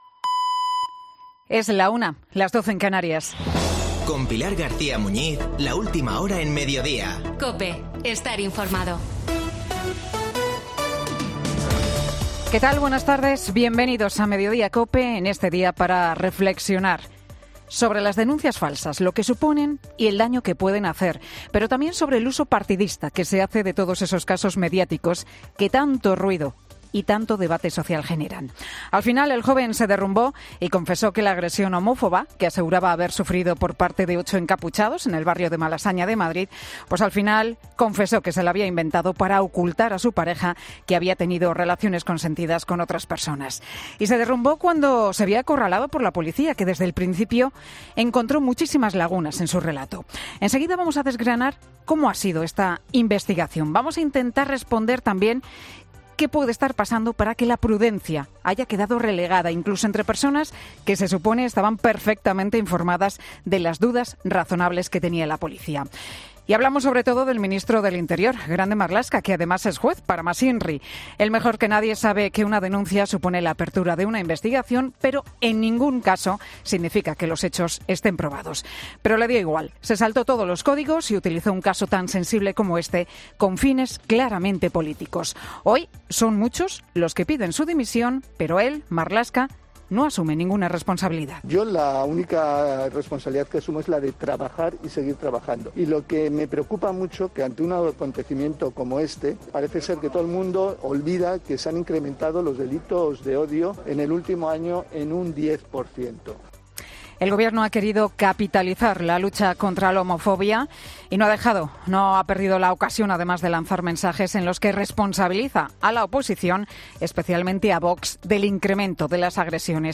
AUDIO: El monólogo de Pilar García Muñiz en Mediodía COPE